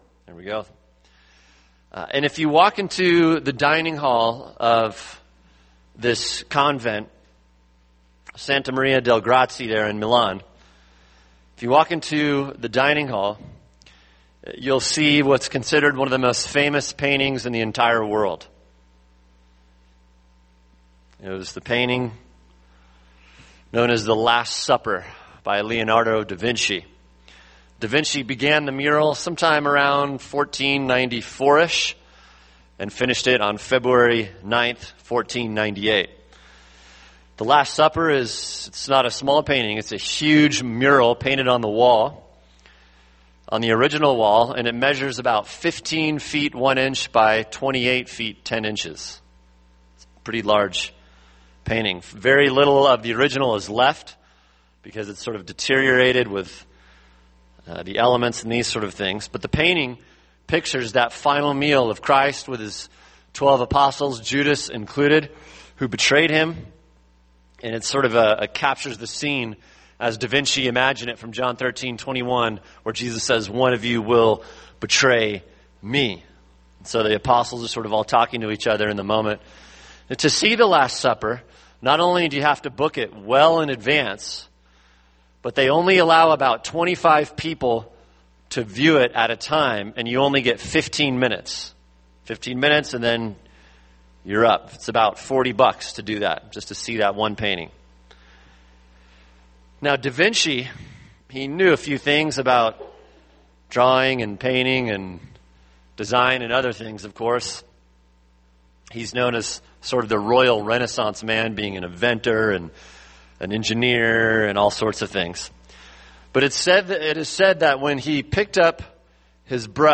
[sermon] Matthew 16:13-17 – Who Do You Say that I Am?